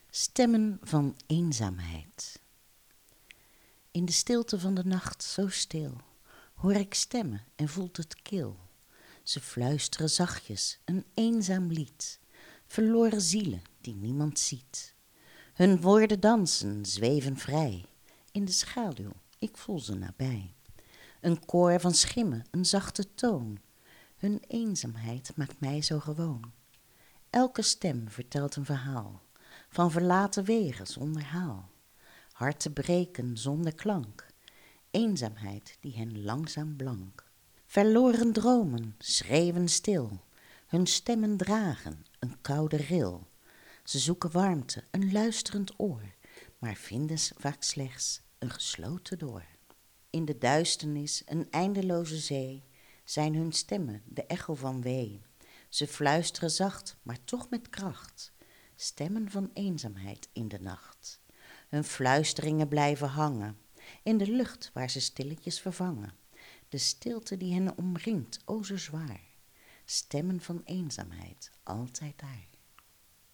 Wekelijks is in ons radioprogramma Kletskoek op de vrijdagochtend, dat tussen�10 en 13 uur live vanuit onze studio wordt uitgezonden, een gedicht te horen.